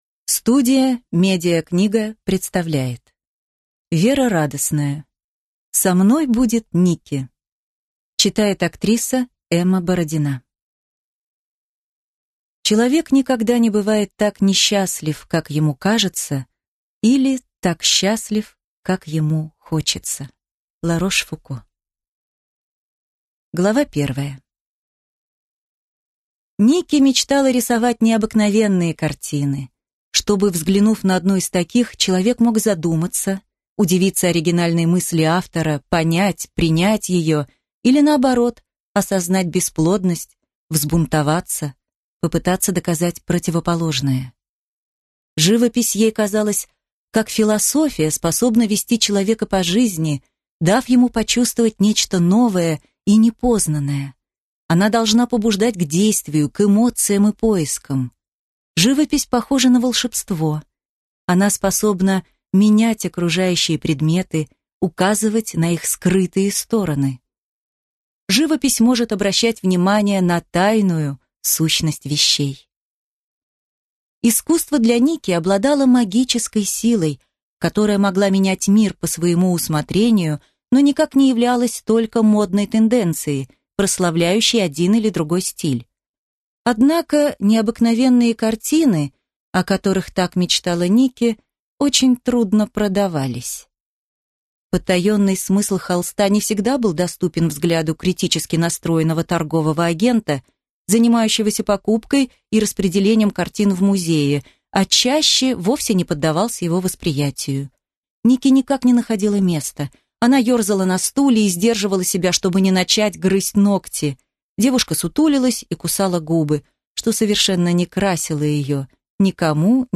Аудиокнига Со мной будет Нике!